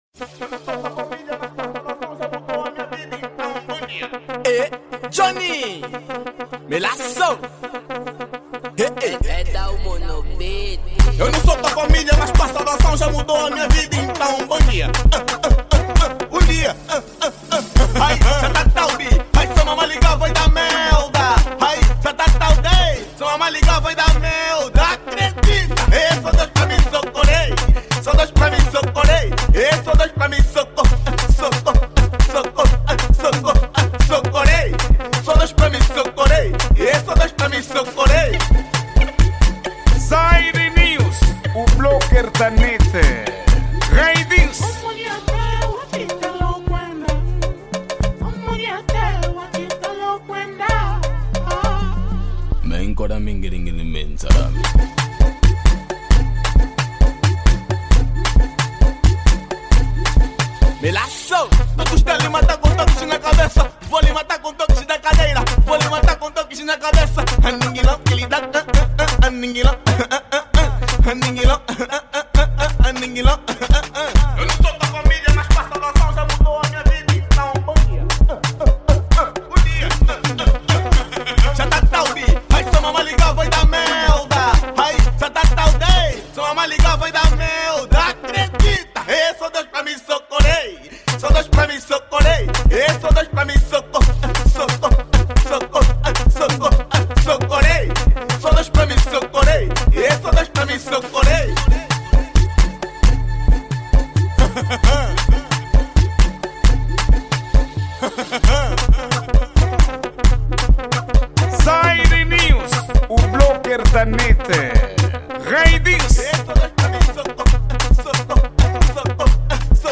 Gênero:Afro House